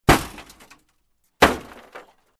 Звуки аварии
Удар в дверь автомобиля